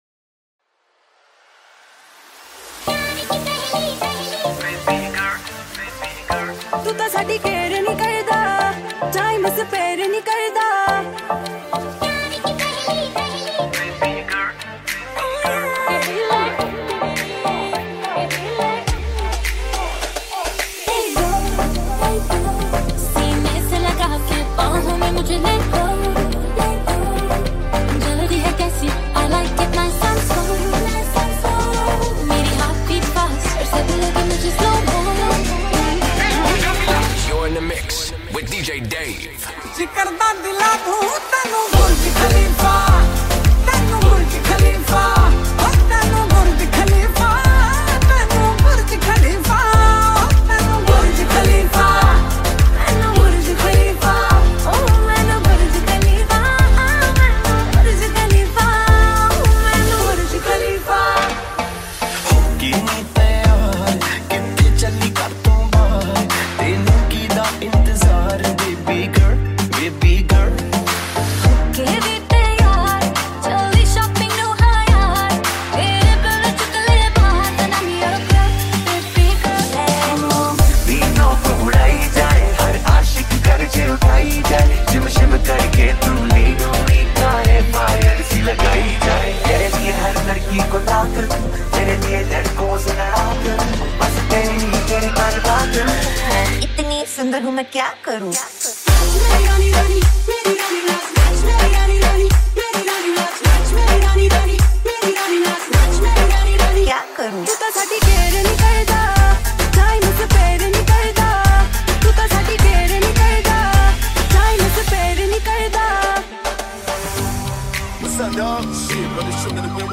High quality Sri Lankan remix MP3 (33.8).